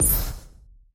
Звук пуф для исчезновения персонажа через облако, как в мультфильмах
• Категория: Исчезновение, пропадание
• Качество: Высокое